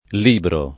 librare v.; libro [ l & bro ]